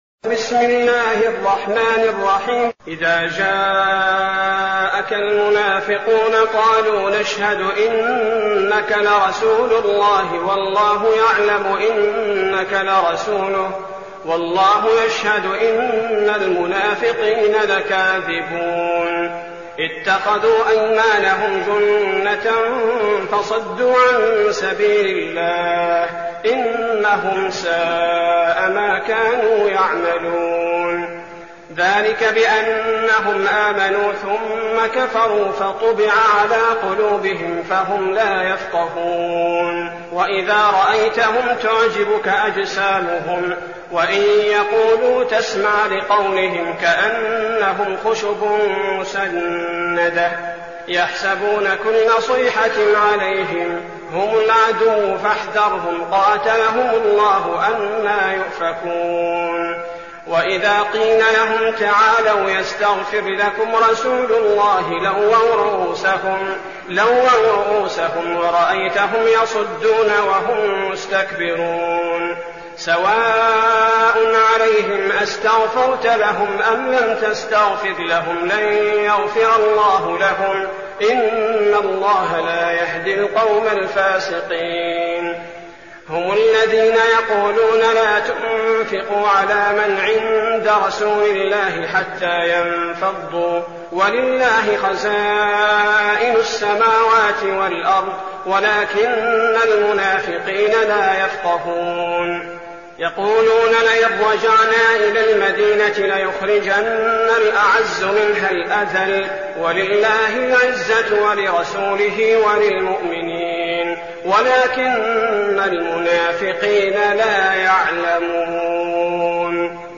المكان: المسجد النبوي الشيخ: فضيلة الشيخ عبدالباري الثبيتي فضيلة الشيخ عبدالباري الثبيتي المنافقون